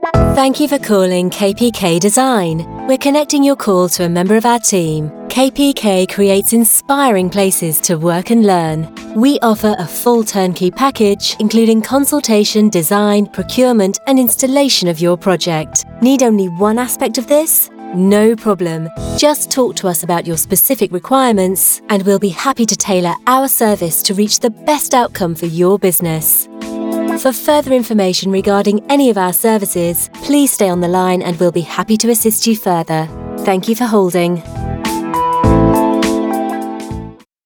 English Female Voice Overs for On Hold Messaging
Accent: RP/Neutral, Southern UK
Tone / Style: Smooth, Warm, Soothing, Easy-on-the-ear, Professional, Corporate